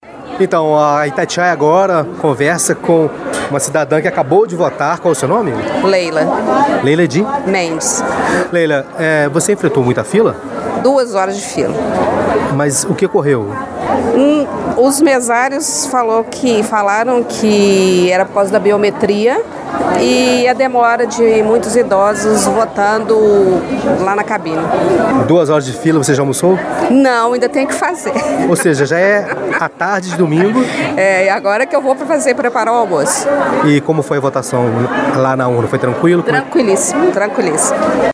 A reportagem da Itatiaia circulou pelas regiões da cidade e se deparou com essa situação em uma seção eleitoral da Escola Estadual Professor José Freire, no Bairro Industrial.